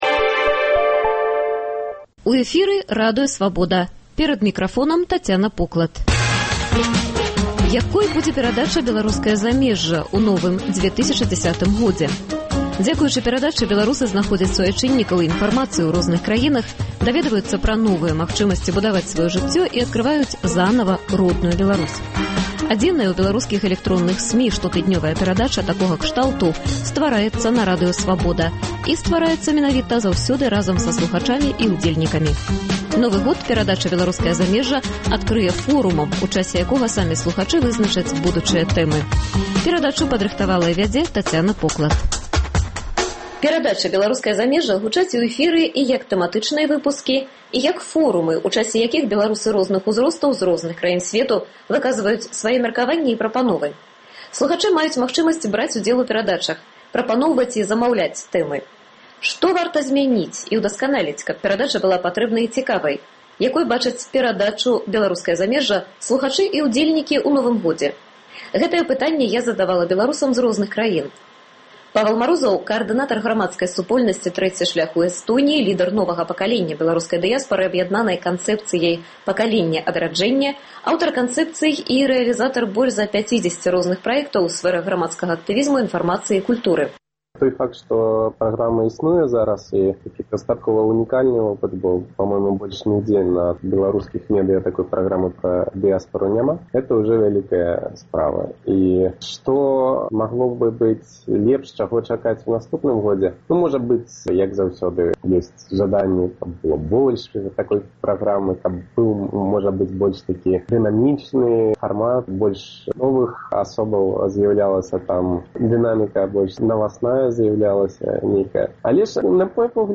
Новы год перадача “Беларускае замежжа” адкрые форумам, у часе якога самі слухачы вызначаць будучыя тэмы.